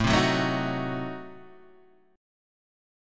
Adim7 chord